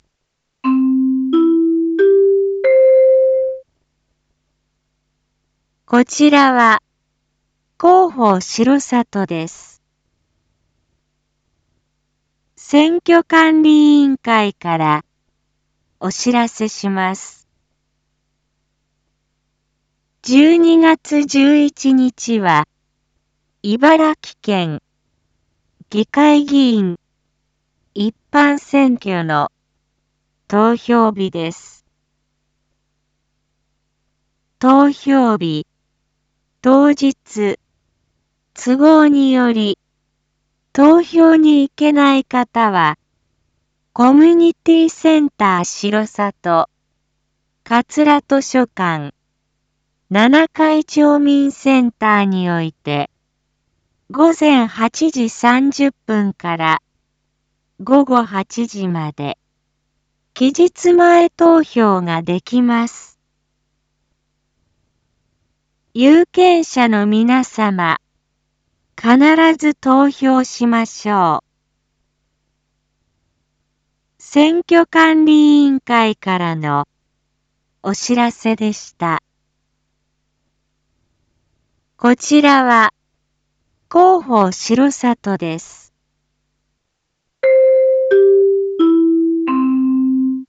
Back Home 一般放送情報 音声放送 再生 一般放送情報 登録日時：2022-12-09 19:01:31 タイトル：県議会議員一般選挙の期日前投票について インフォメーション：こちらは、広報しろさとです。